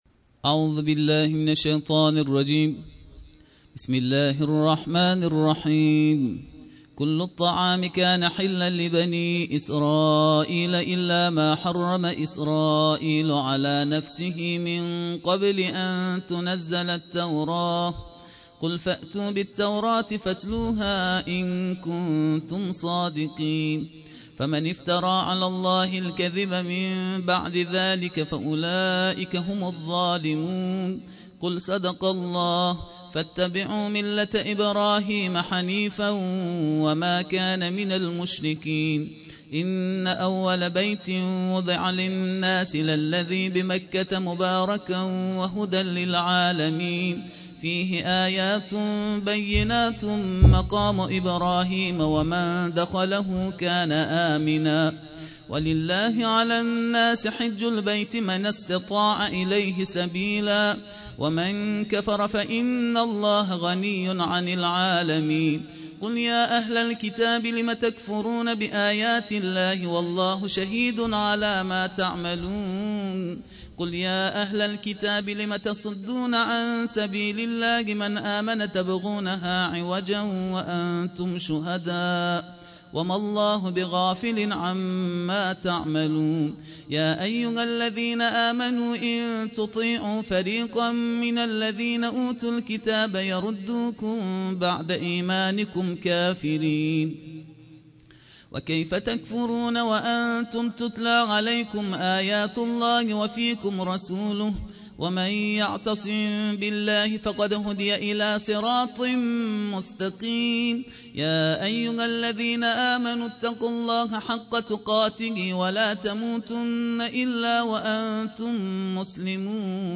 گزارش صوتی دویست و سی‌امین کرسی تلاوت و تفسیر قرآن کریم - پایگاه اطلاع رسانی ضیافت نور
ترتیل جزء ۴ قرآن کریم